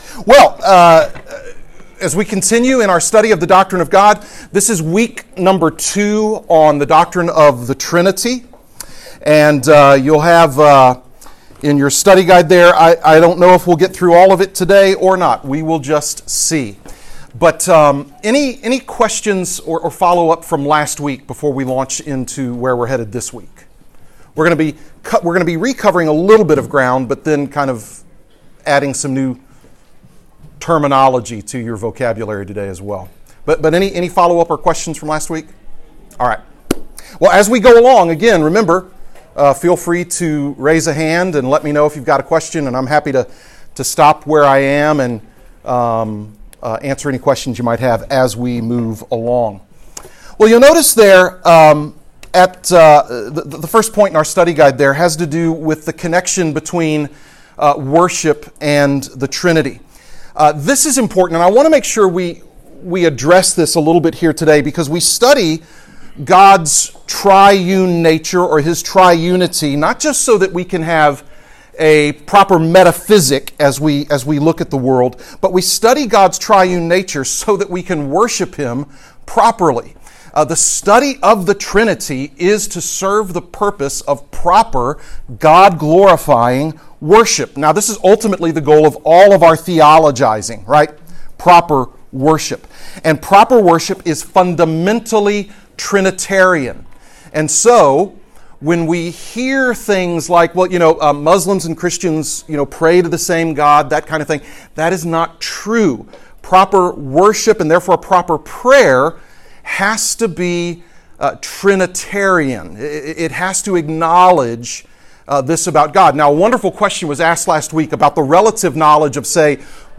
Portions of the audio have been edited during times of class interaction due to low sound quality.